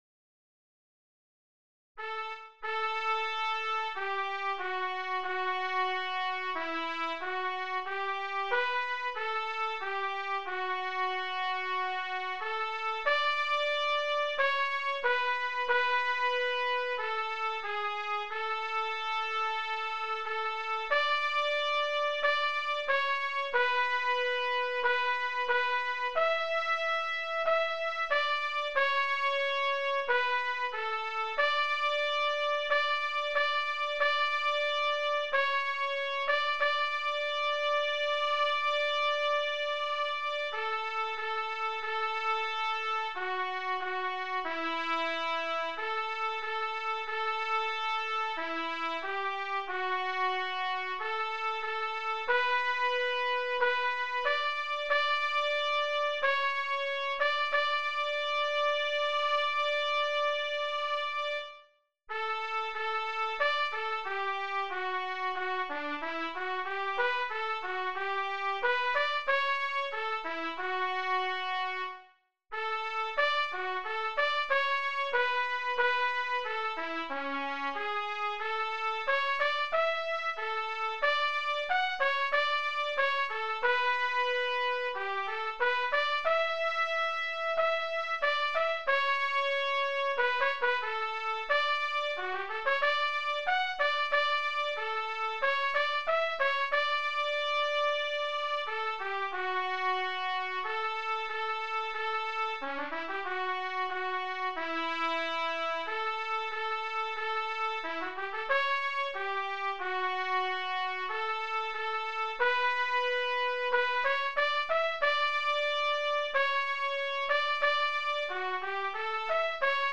DIGITAL SHEET MUSIC - TRUMPET SOLO
Sacred Music, Hymns, Unaccompanied Solo